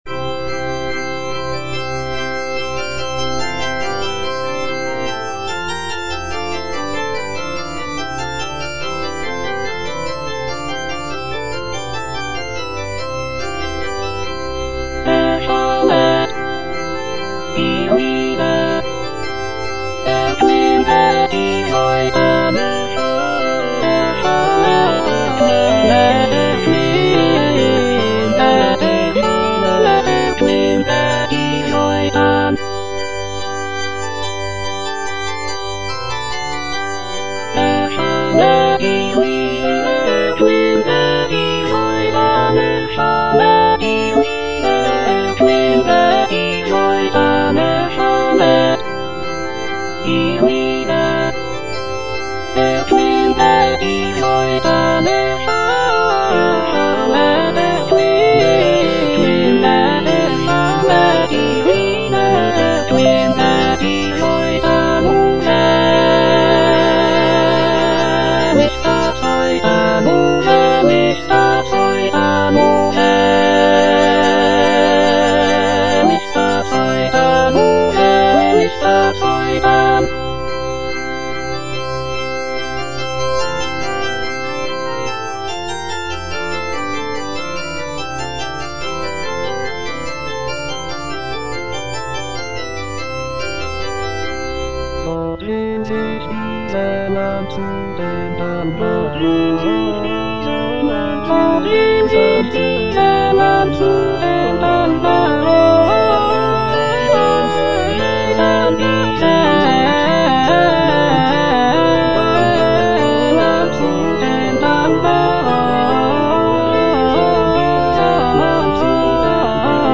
J.S. BACH - CANTATA "ERSCHALLET, IHR LIEDER" BWV172 (EDITION 2) Erschallet, ihr Lieder - Alto (Emphasised voice and other voices) Ads stop: auto-stop
It features a jubilant opening chorus, expressive arias, and intricate chorales. The text celebrates the coming of the Holy Spirit and the birth of the Christian Church. The music is characterized by its lively rhythms, rich harmonies, and intricate counterpoint.